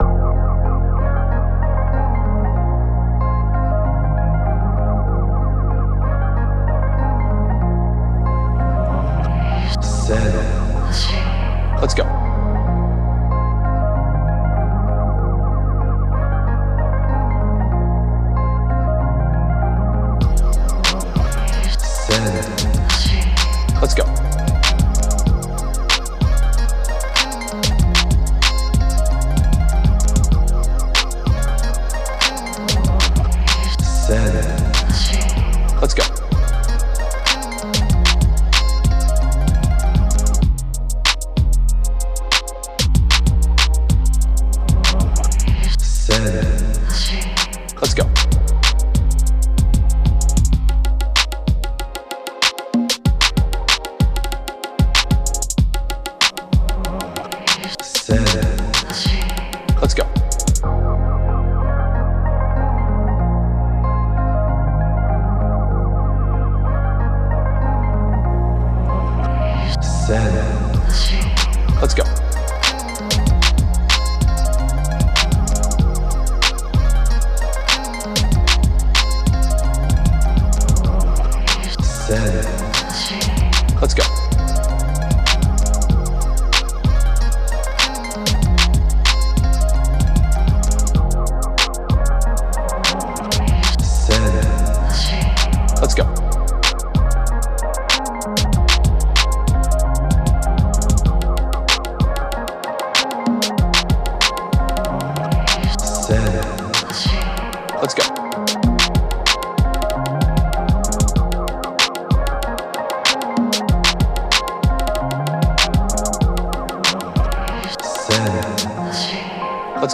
Bouncy – Trapsoul – Type Beat
Key: F Minor
95 BPM